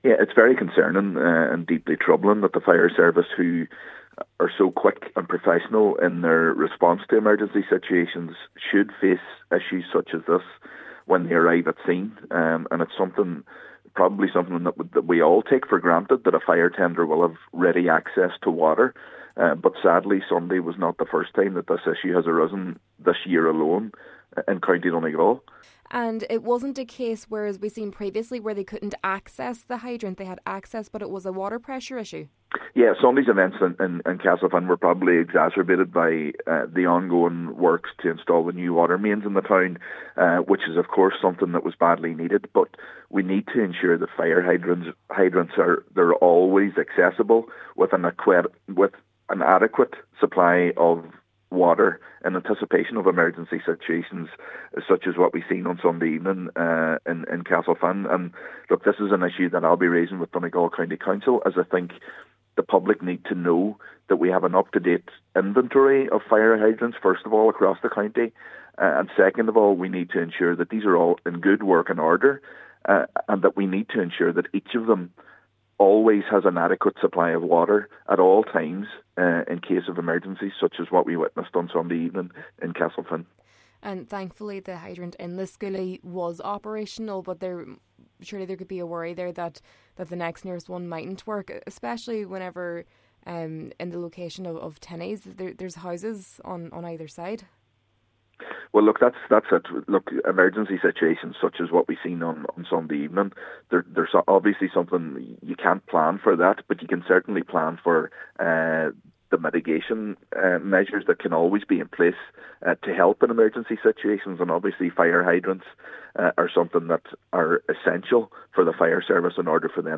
Cllr. Gary Doherty says hydrants need to be at the ready for when an emergency arises: